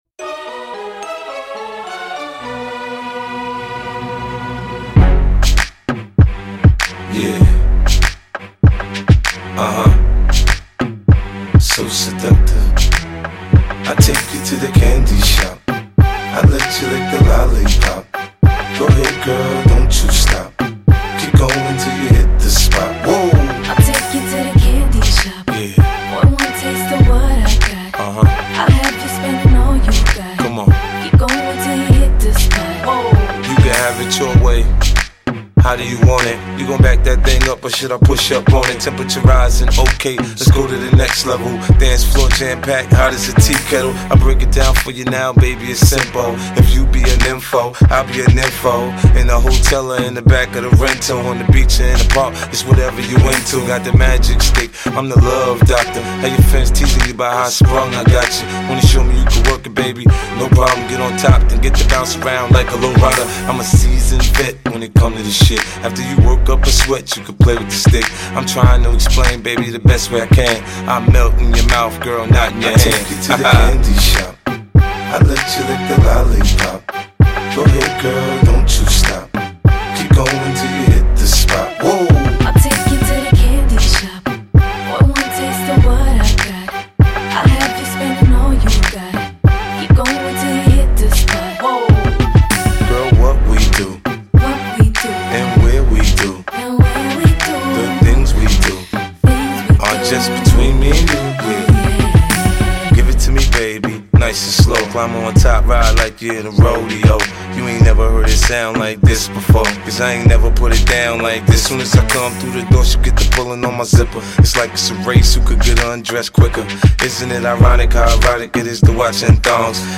HipHop 2000er